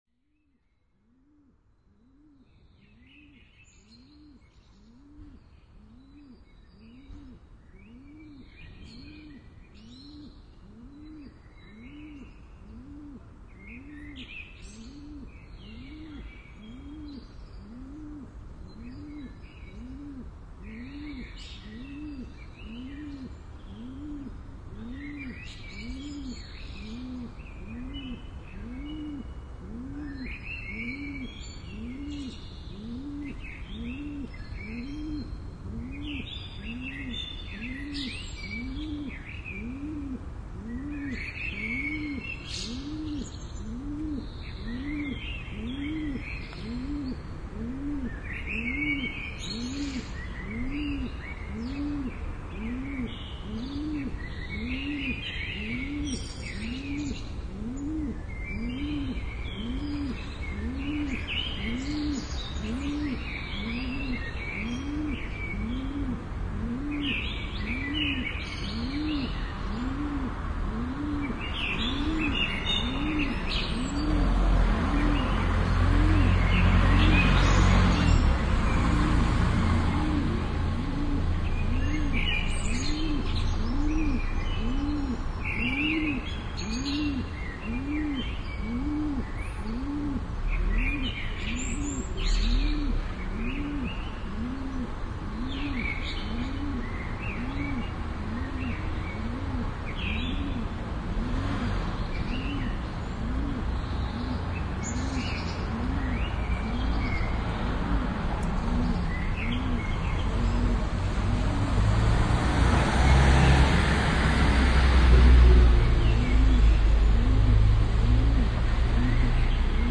15 fieldrecordings, soundscape- and soundcompositions
13 Fieldrecording, Hamburg-St. Pauli, Germany, 25.03.2005
His music bounces back and forth between the inaudible and the more present, louder parts.
The field recordings are pretty much well-transformed beyond the original, but of and on, children voices and bird calls sound through.